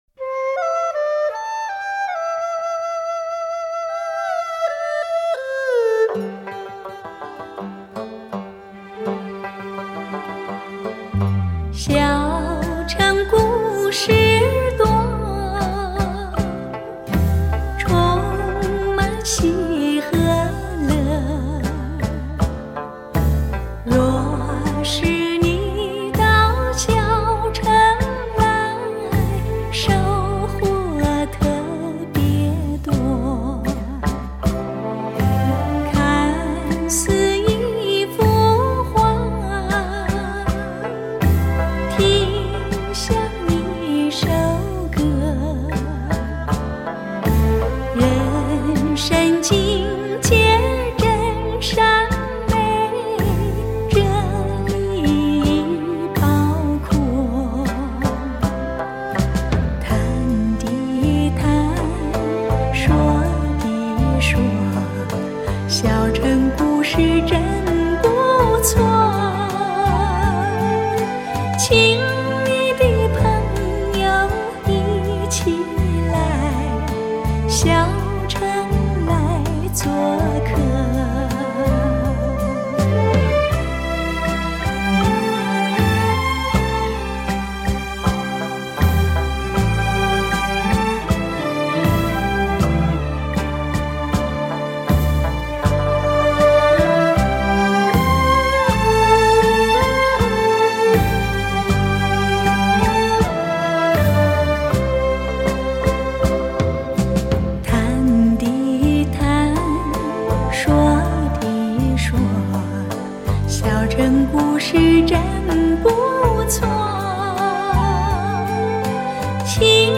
以当今至高规格音效处理
DXD重新编制